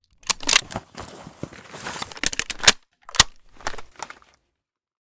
vityaz_reload.ogg